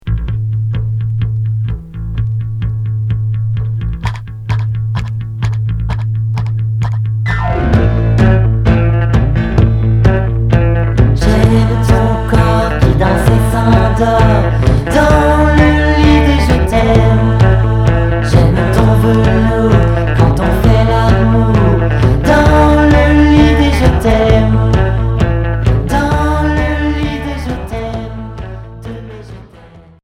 Glam